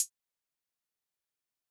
SouthSideHihat2.wav